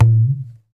Closed Hats
07_Perc_02_SP.wav